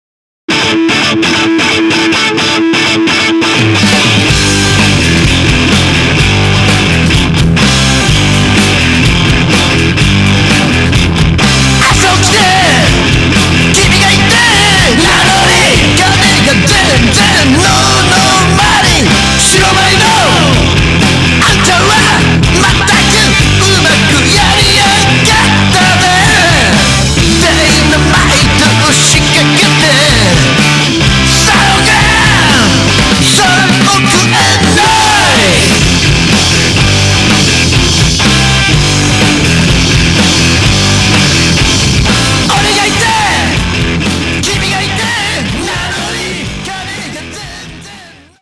ロックンロール